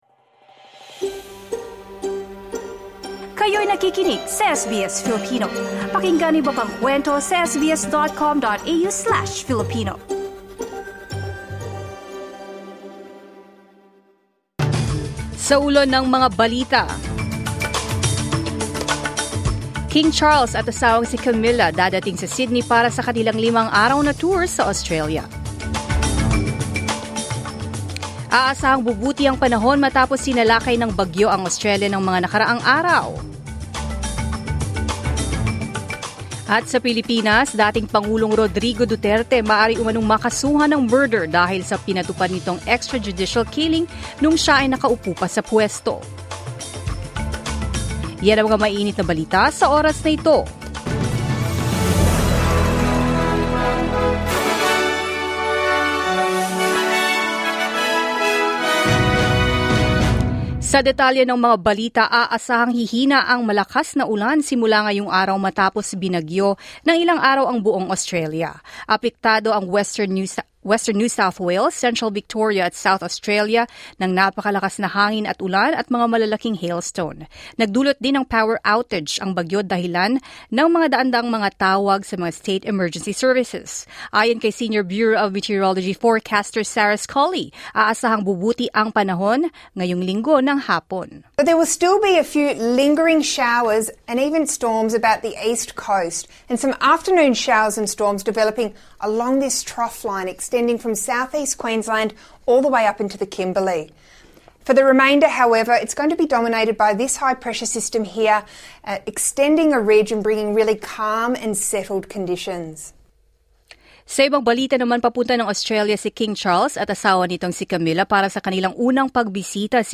Mga balita ngayong ika 19 ng Oktubre 2024